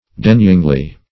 denyingly - definition of denyingly - synonyms, pronunciation, spelling from Free Dictionary Search Result for " denyingly" : The Collaborative International Dictionary of English v.0.48: Denyingly \De*ny"ing*ly\, adv. In the manner of one denies a request.